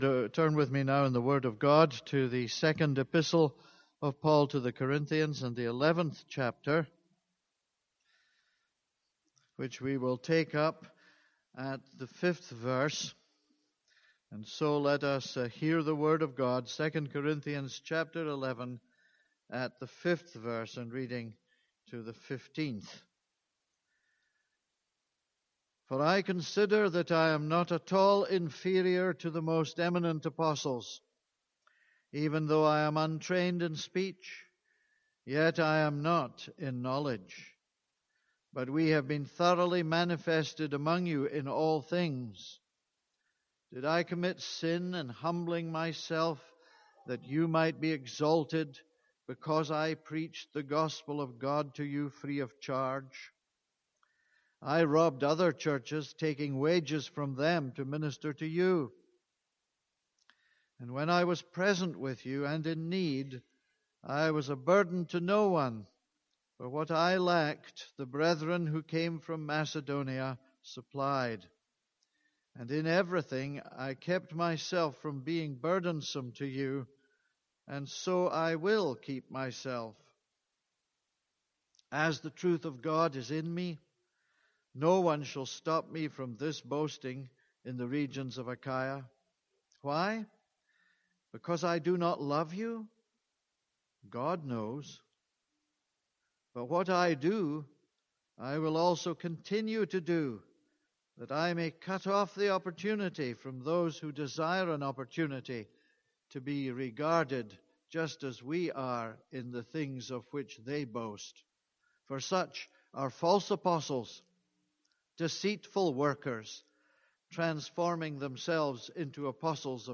This is a sermon on 2 Corinthians 11:5-15.